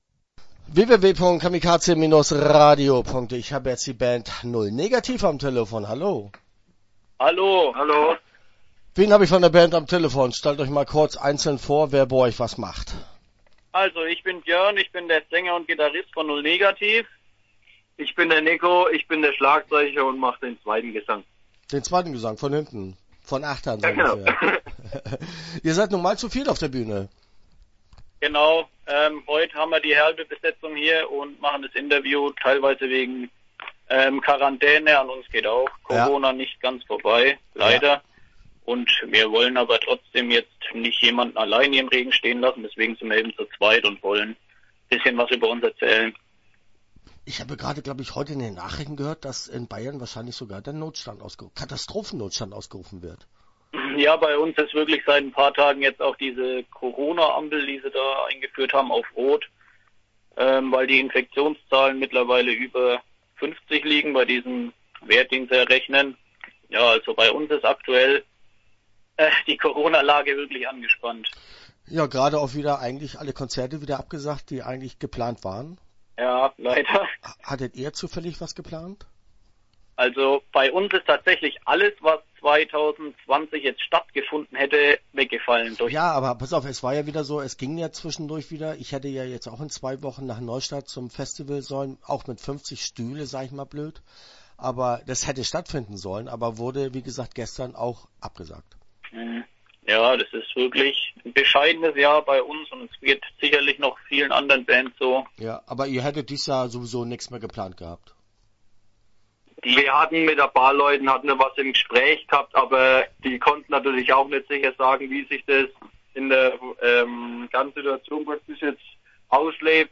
Null Negativ - Interview Teil 1 (10:54)